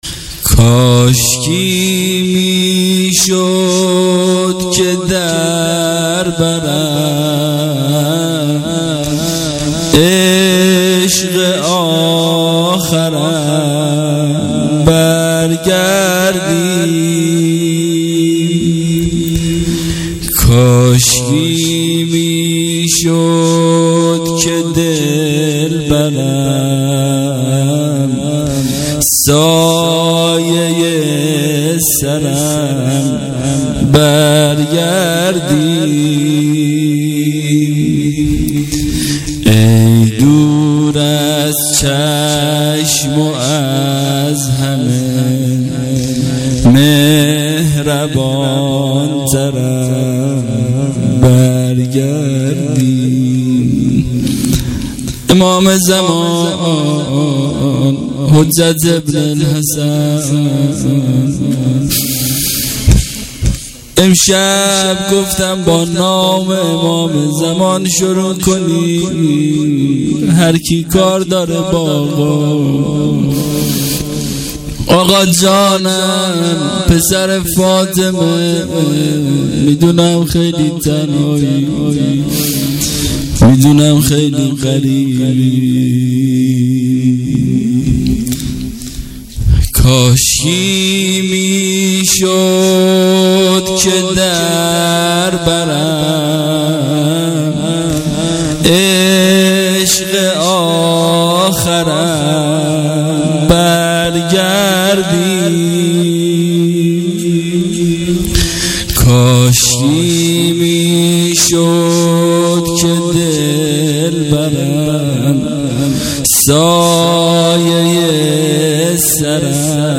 توسل به حضرت معصومه (س)98